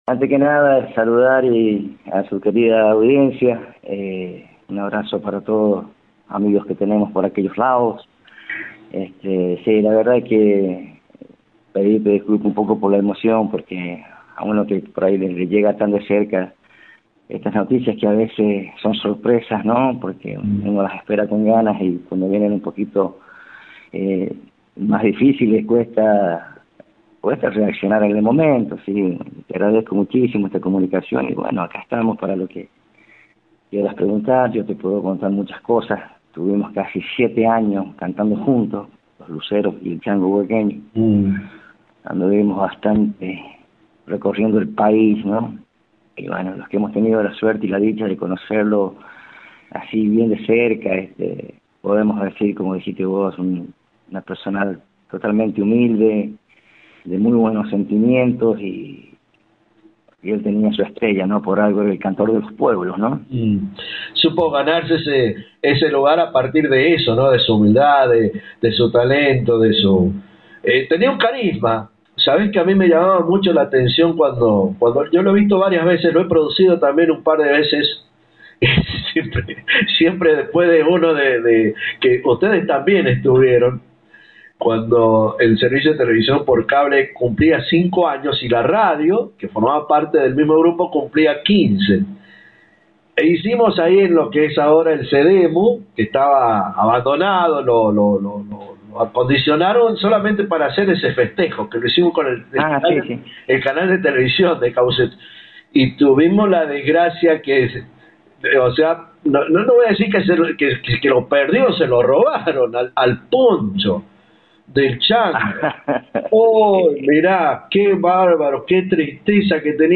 charló con Radio Genesis para referirse a la vida y obra del Chango y la viviencia artistica de mas de 7 años con el Cantor popular.